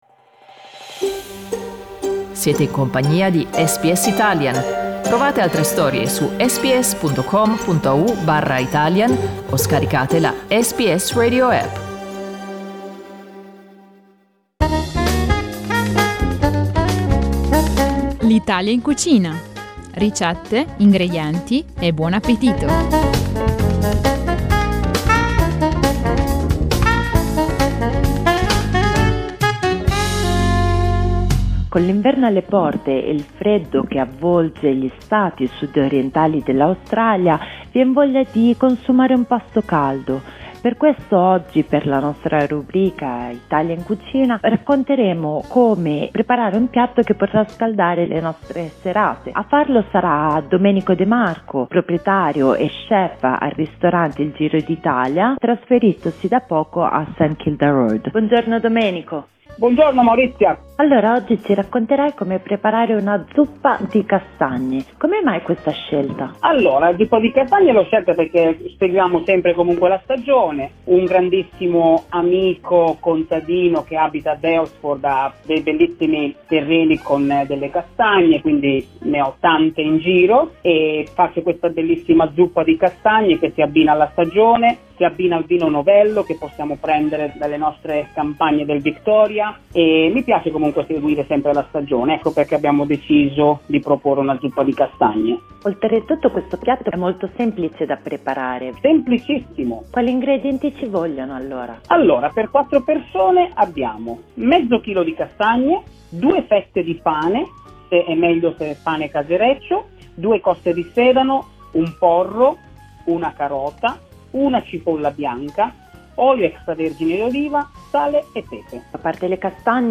For advice on how to present the soup or which wine to combine, listen to the interview (only available in Italian) LISTEN TO Zuppa di castagne con pane casereccio SBS Italian 06:34 Italian Listen to SBS Italian every day from 8am to 10am.